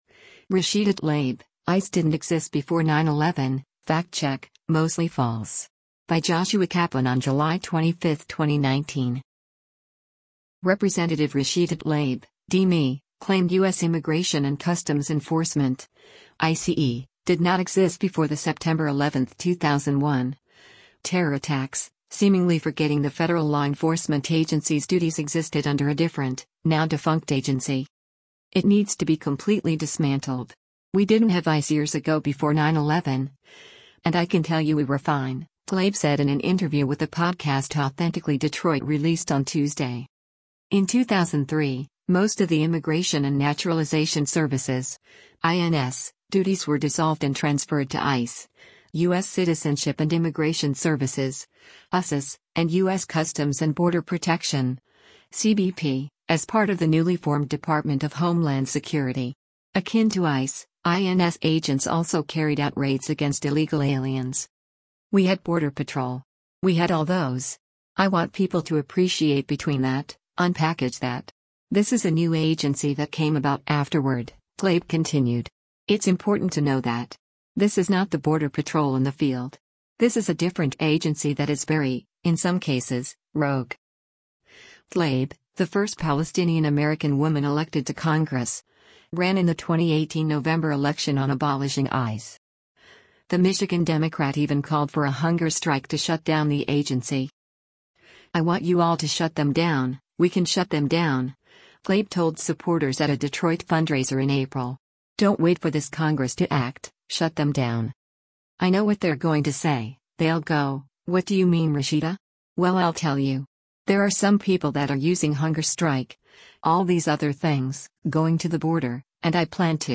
“It needs to be completely dismantled. We didn’t have ICE years ago before 9/11, and I can tell you we were fine,” Tlaib said in an interview with the podcast Authentically Detroit released on Tuesday.